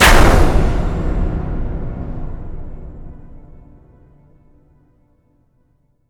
LC IMP SLAM 9.WAV